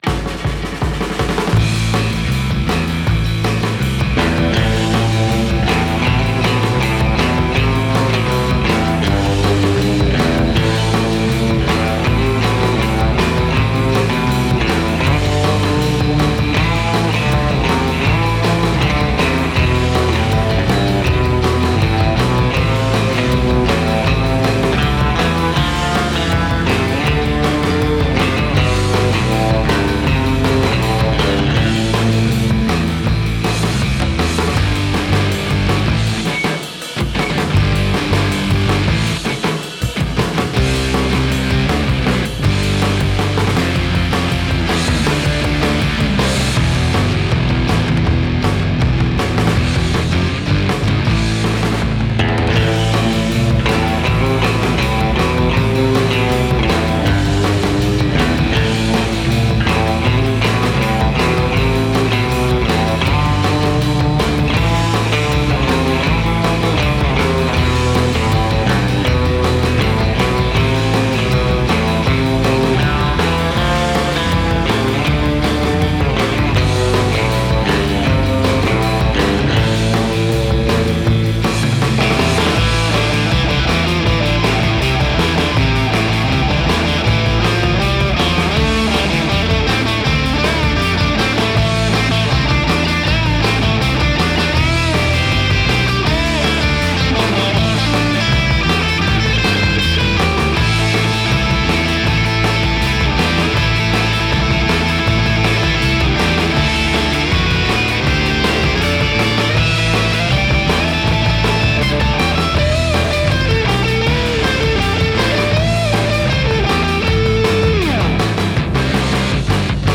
This is a surf-rock instrumental.
Musically, the song is almost purely diatonic, being in A minor (I think there’s one moment that’s a G# passing chord).
The guitars and basses are DIed with Amplitube. The drums are the Kontakt ’50s Drum sample library. There is an absolute smearing of reverb on the tracks because this is surf rock, after all.